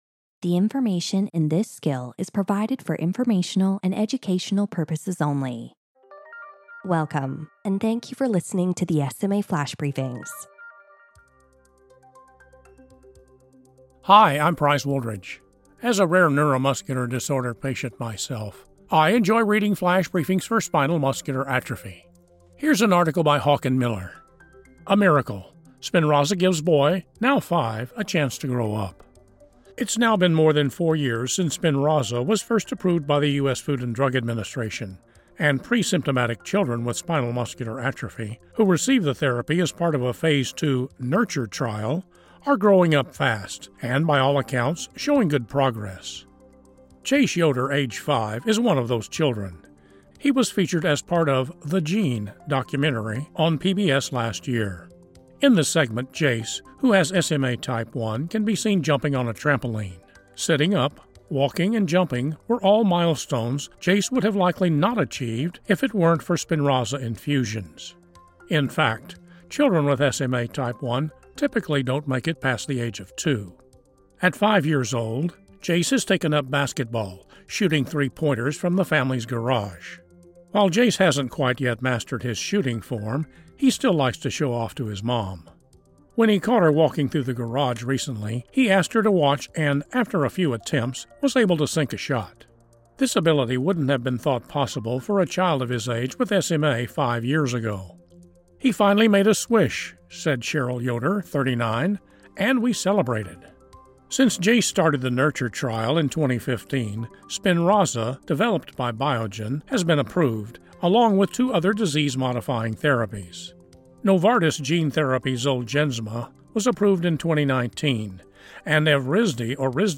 reads a column